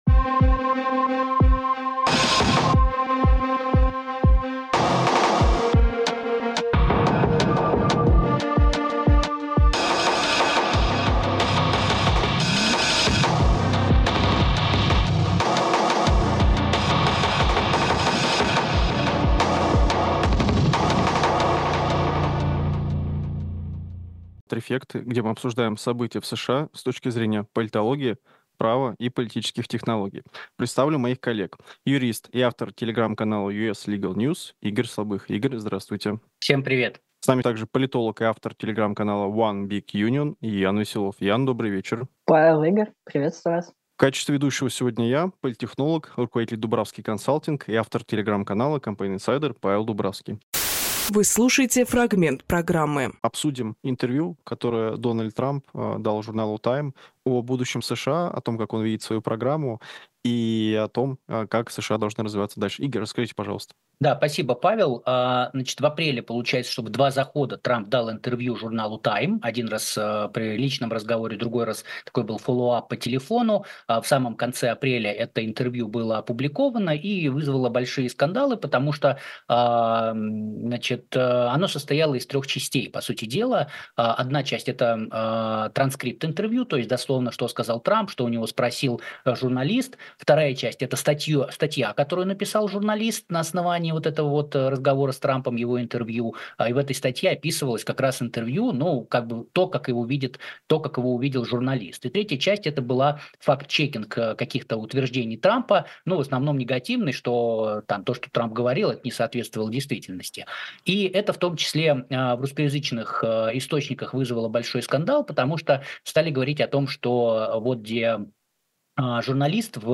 Фрагмент эфира от 14 мая.